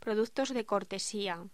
Locución: Productos de cortesía
Sonidos: Voz humana